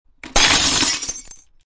Download Smash sound effect for free.
Smash